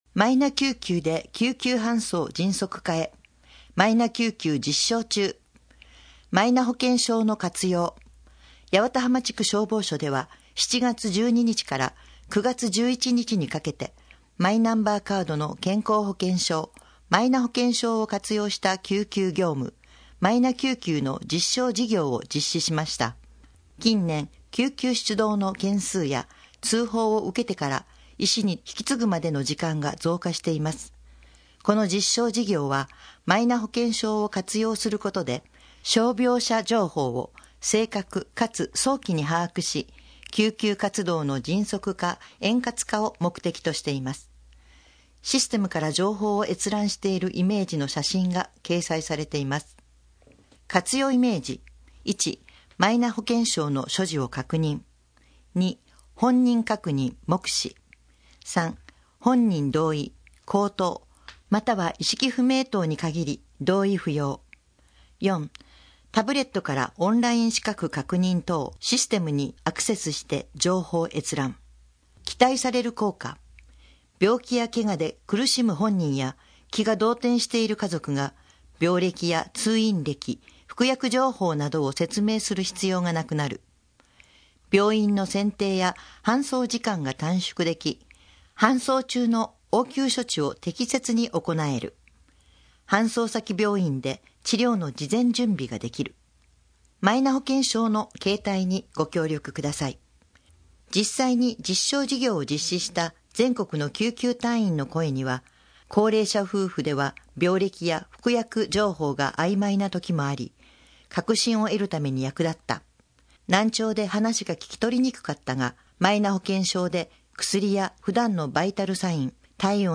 目の不自由な方にも市政の動きを知っていただくため、「広報やわたはま」をカセットテープやCDに吹き込み、希望者に配布しています。
なお「声の広報」は、朗読ボランティアどんぐりの協力によって作成しています。